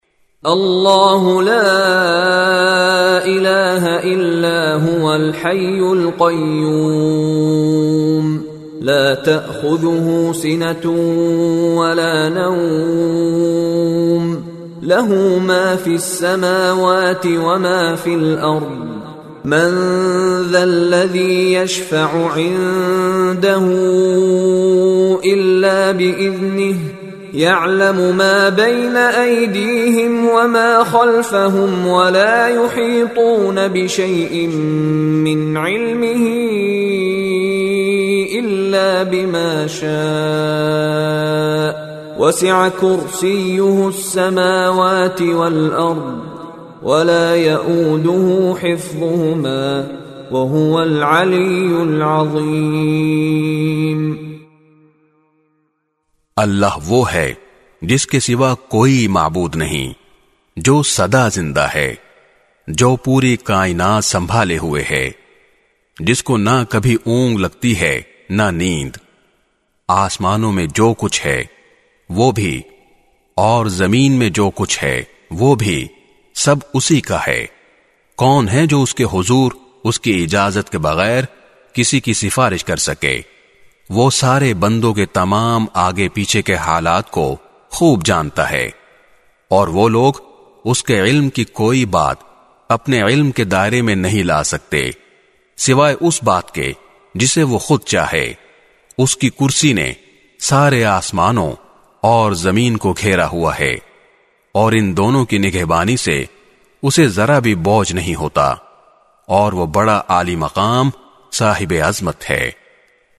Complete Ayat ul Kursi Audio Recitation With Urdu Hindi Translation
CategoryTilawat
His melodious voice and impeccable tajweed are perfect for any student of Quran looking to learn the correct recitation of the holy book.
Complete Ayat ul Kursi Audio Recitation With Urdu Hindi Translation.mp3